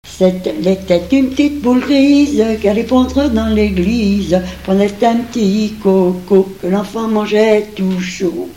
Berceuses diverses
Genre énumérative
Pièce musicale inédite